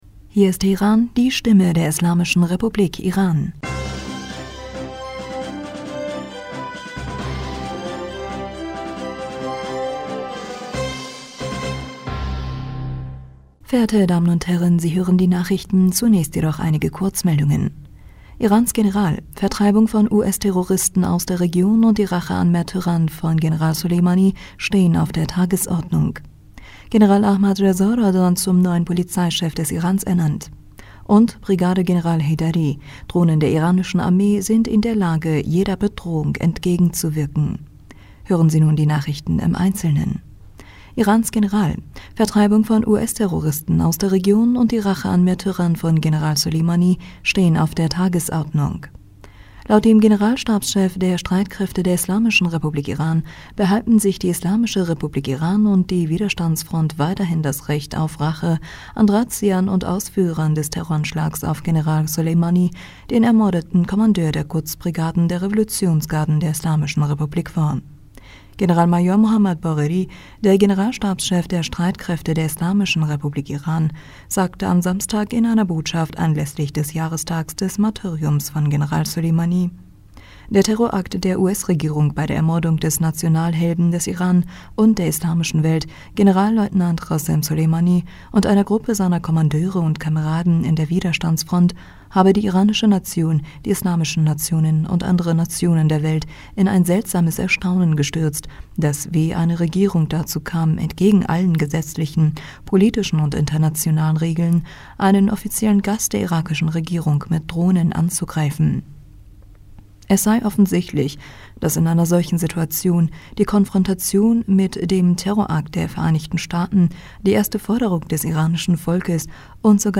Nachrichten vom 08. Januar 2023